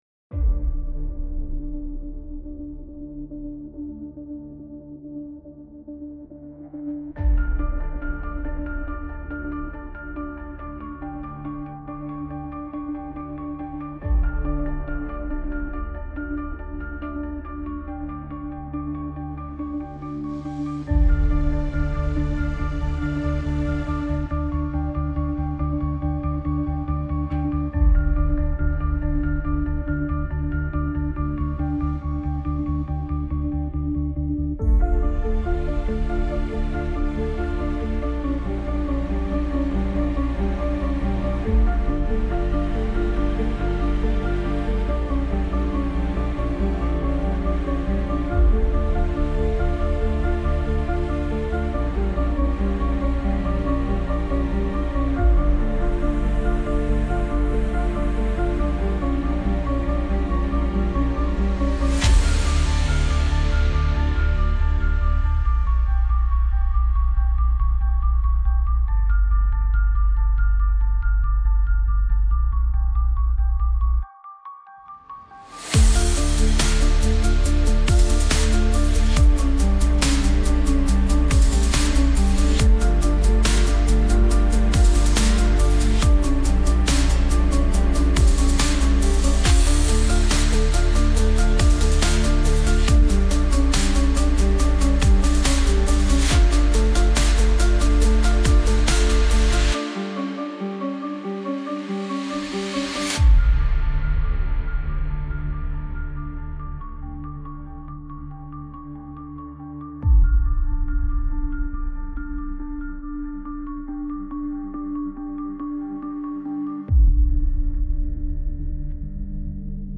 Lost hope -dark background 3.43.mp3